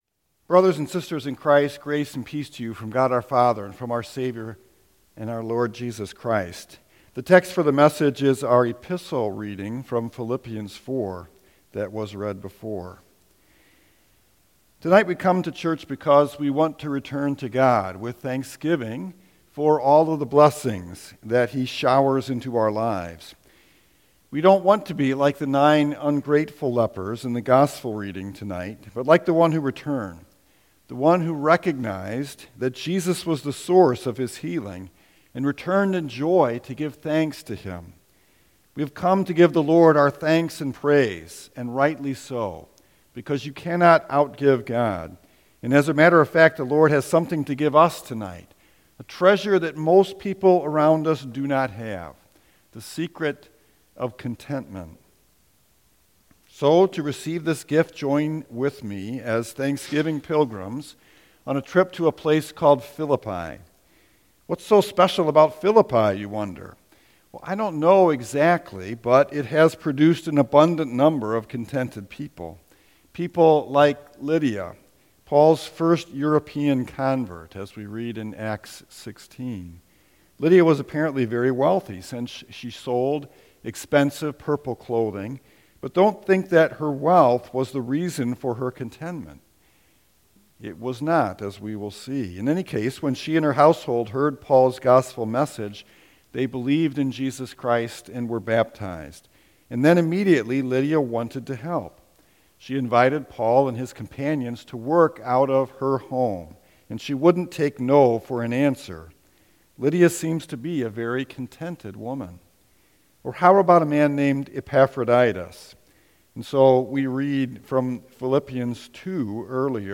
Thanksgiving message